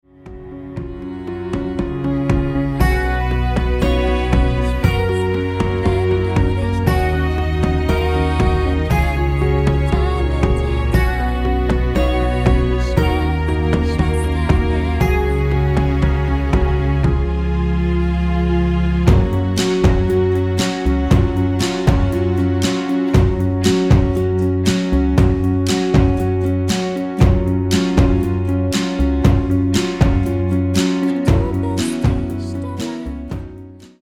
Mit Backing Vocals